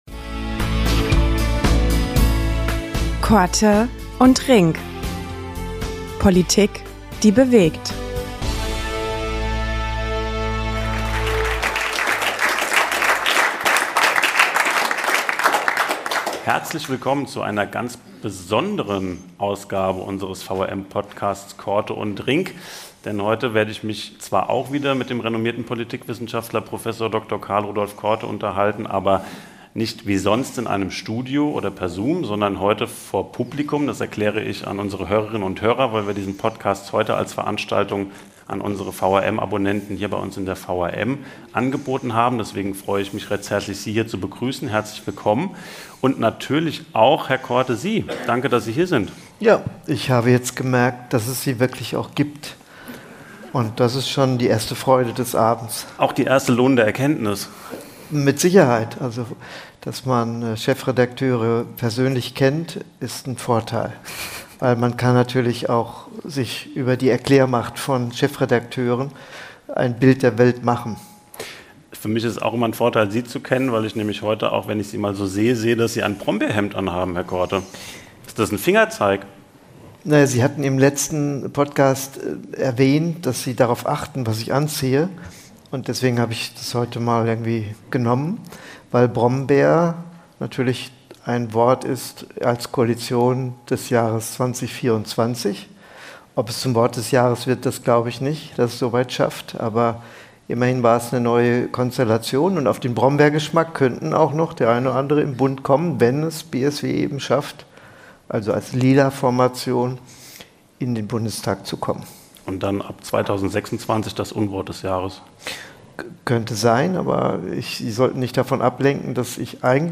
Vor Publikum geht es um den AfD- und SPD-Parteitag, Radikalisierung in der innerdeutschen Politik und aktuelle Entwicklungen im "Turbo"-Bundeswahlkampf.